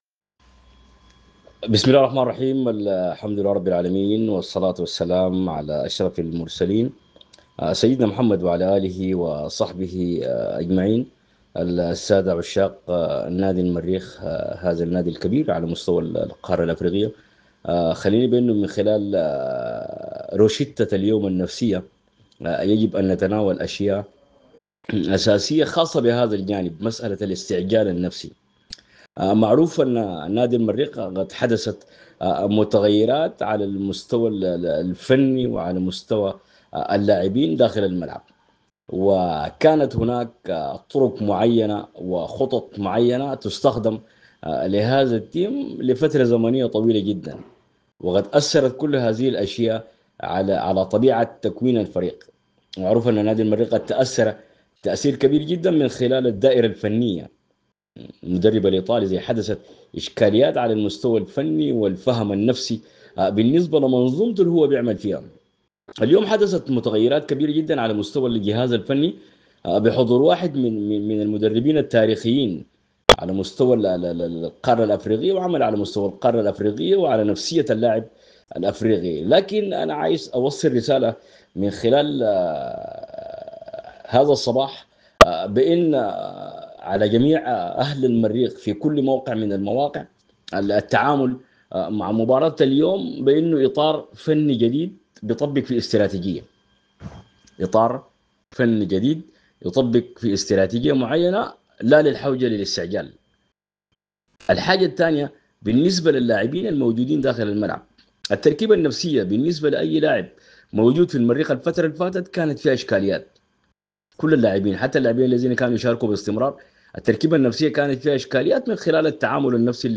حوارات